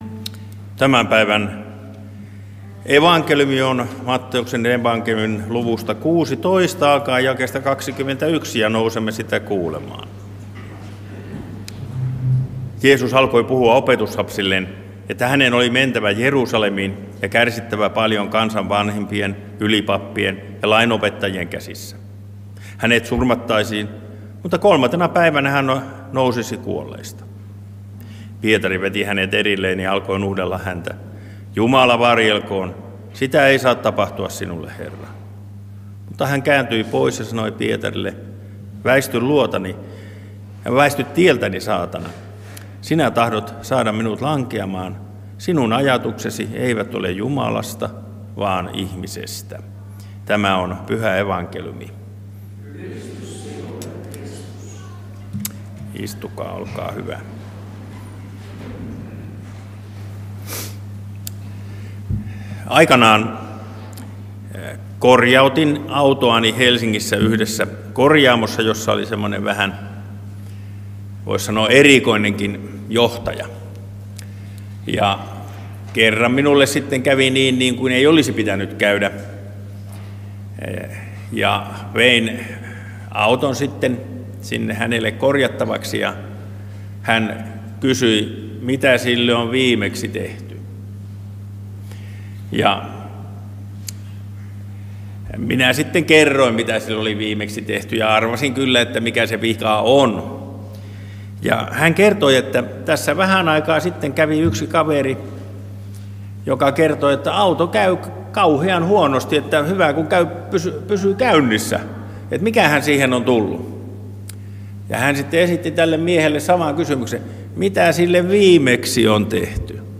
saarna Lahdessa 1. paastonajan sunnuntaina